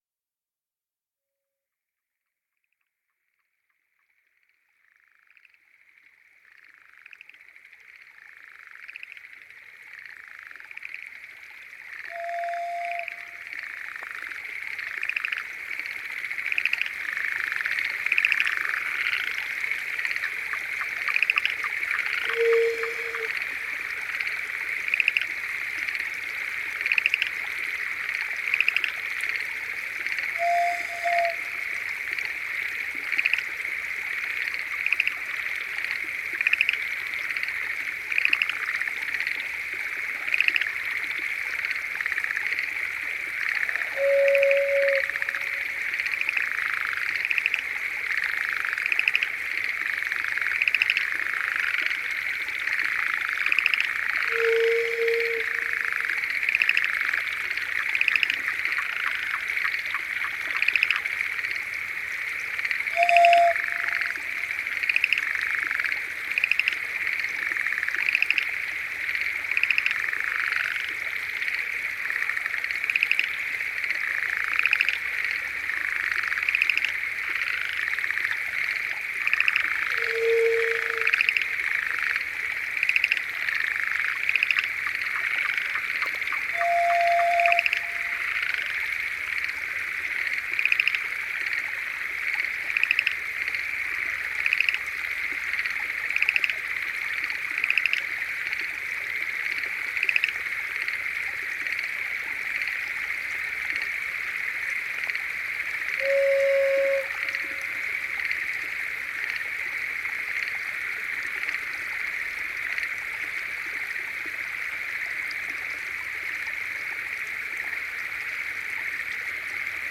耳を澄ますことでしか捉えることの出来ない音の在り処を、端正な音像で示したフィールド録音の大傑作！
という作業の果てに産まれた、フィールド録音を元に制作された“音”の作品です！